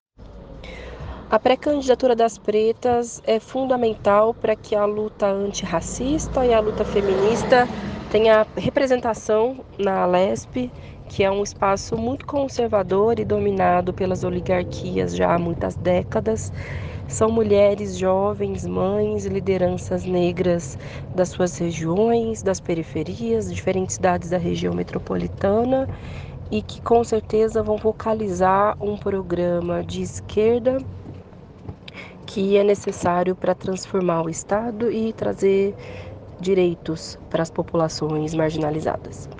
Sábado (25/06) o Jornal Empoderado esteve na região da Zona Sul de São Paulo, no ato político no Sindicato dos Professores de São Paulo.
Fala da Deputada Federal Sâmia Bonfim